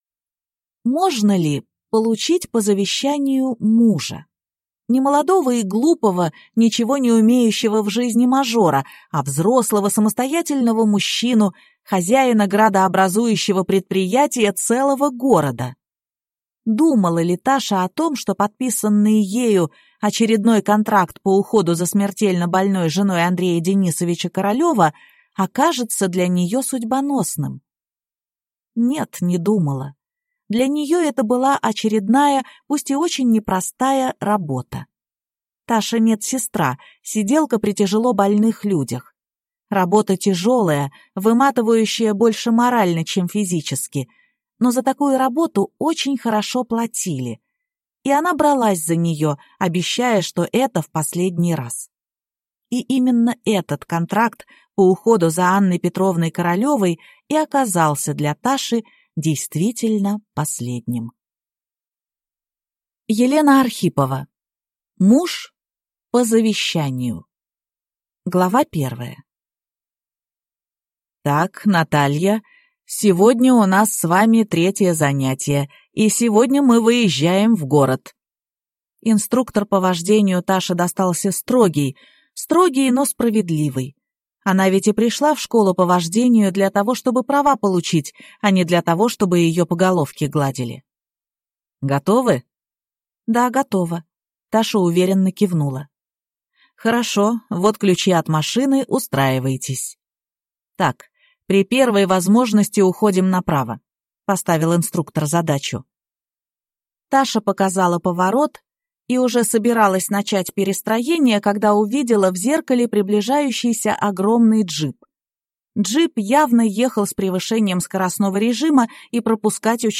Аудиокнига Муж по завещанию | Библиотека аудиокниг